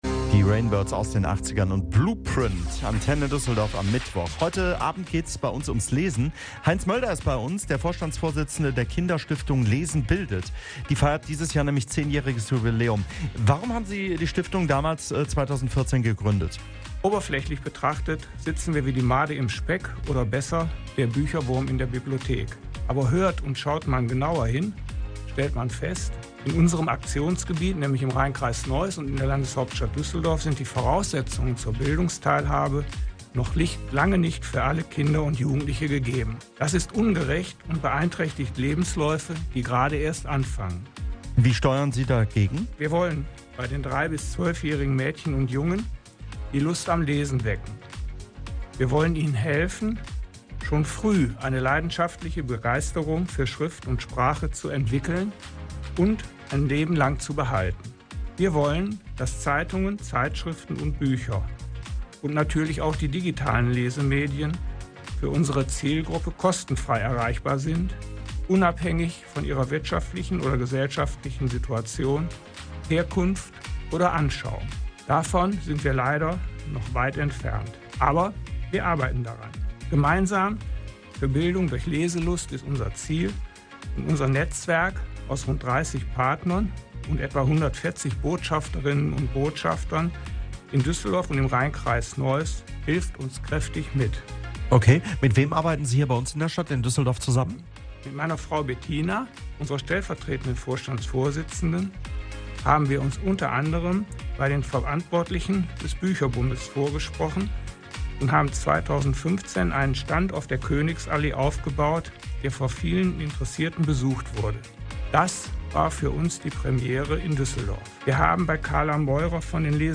HIER „LIVE“ REINHÖREN IN DAS SPANNENDE INTERVIEW!